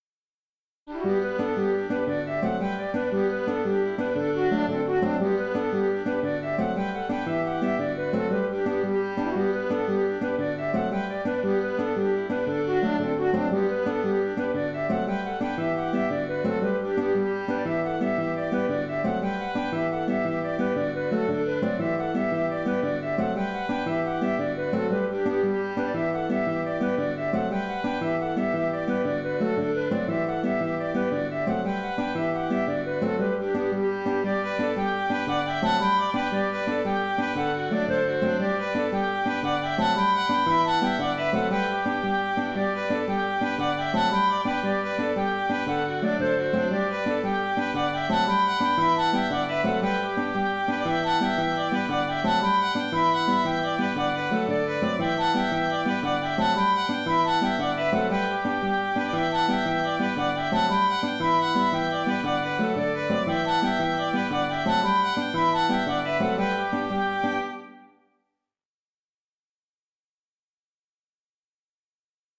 Saddle the Pony (Jig) - Musique irlandaise et écossaise